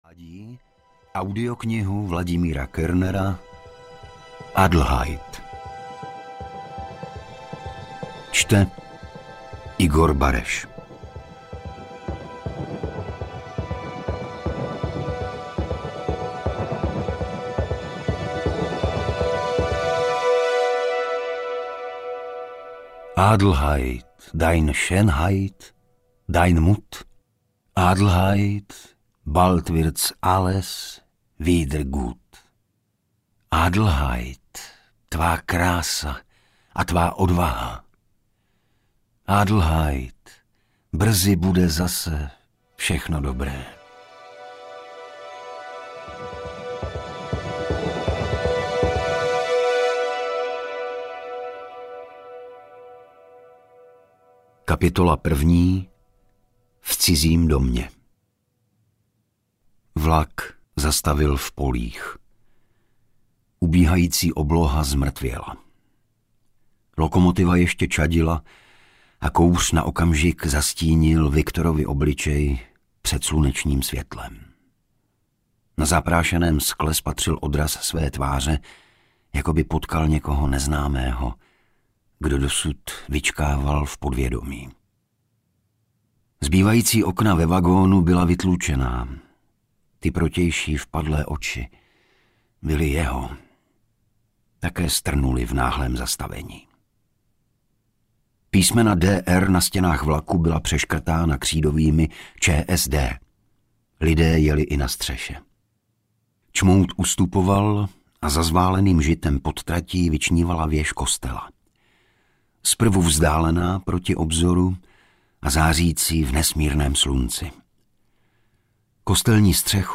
Adelheid audiokniha
Ukázka z knihy
• InterpretIgor Bareš